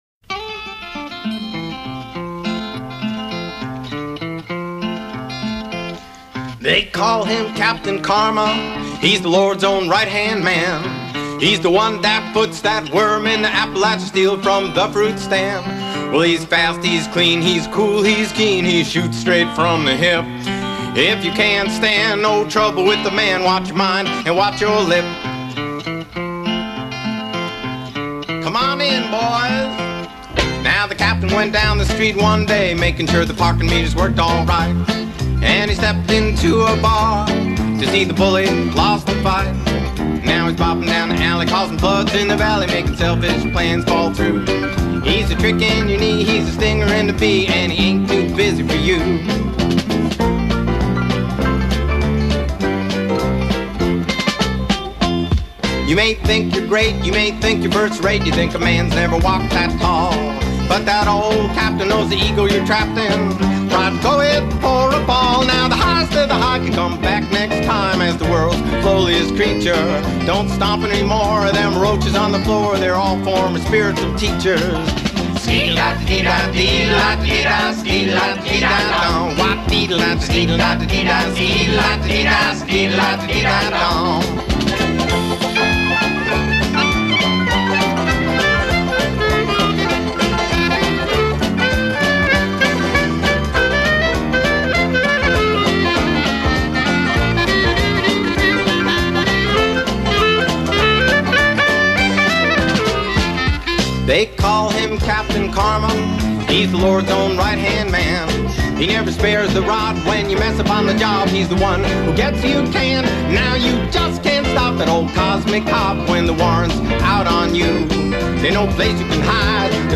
Lead vocal and guitar
banjo
bass guitar
clarinet
backup vocals